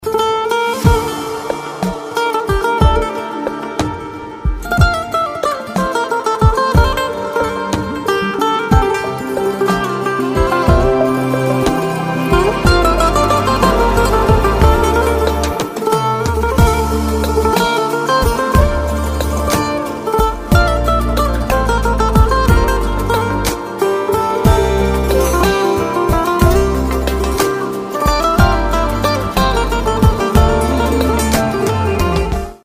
رینگتون عاشقانه بی کلام